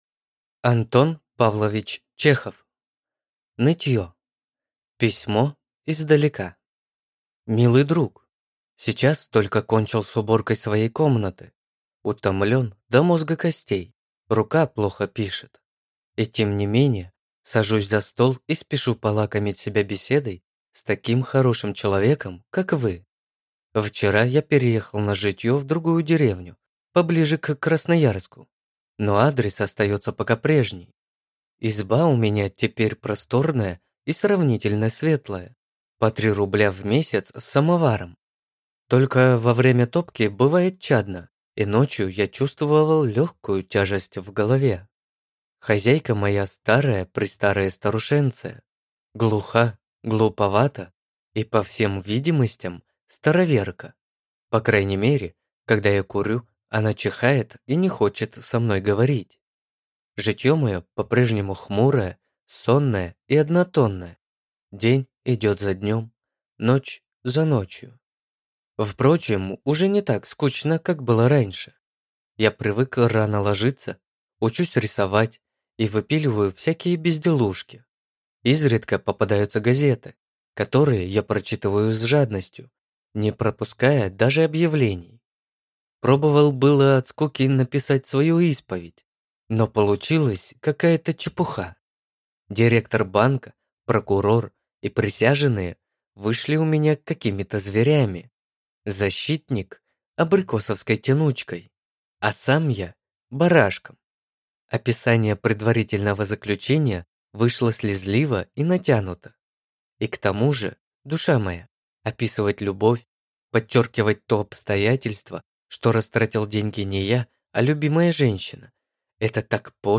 Аудиокнига Нытье | Библиотека аудиокниг